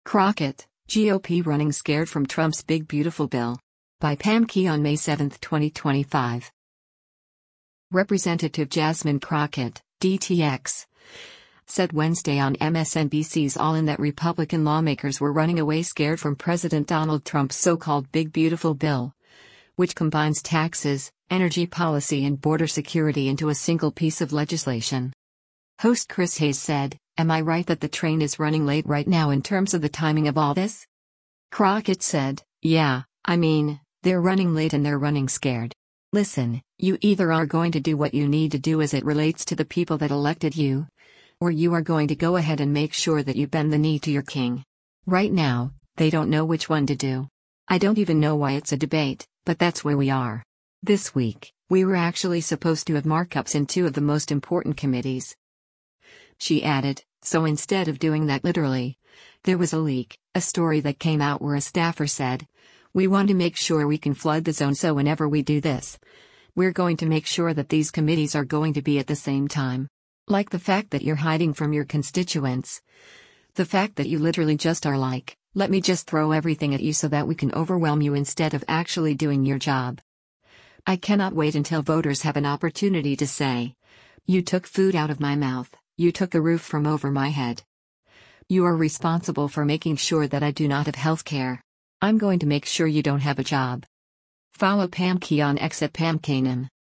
Representative Jasmine Crockett (D-TX) said Wednesday on MSNBC’s “All In” that Republican lawmakers were running away “scared” from President Donald Trump’s so-called big beautiful bill, which combines taxes, energy policy and border security into a single piece of legislation.
Host Chris Hayes said, “Am I right that the train is running late right now in terms of the timing of all this?”